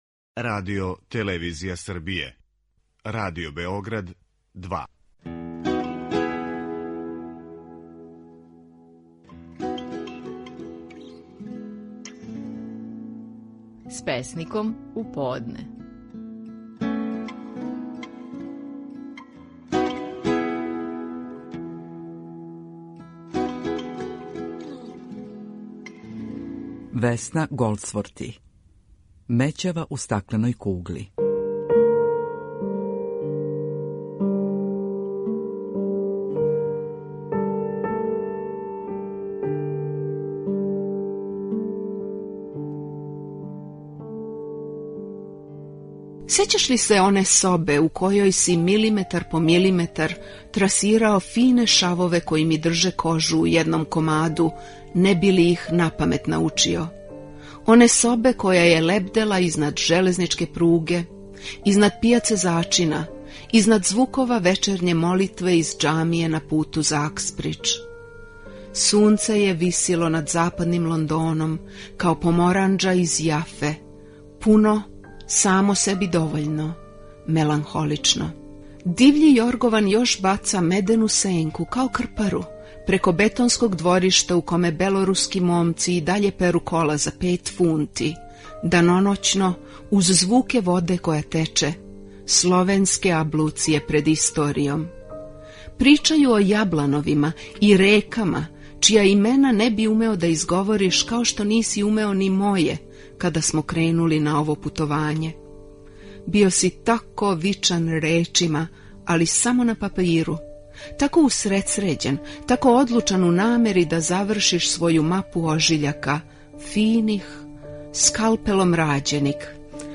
Стихови наших најпознатијих песника, у интерпретацији аутора.
Весна Голдсворти говори песму: „Мећава у стакленој кугли".